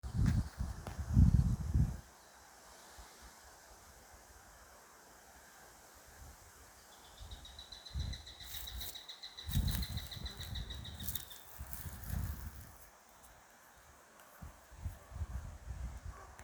Zvirbuļvanags, Accipiter nisus
Ad- U (audio).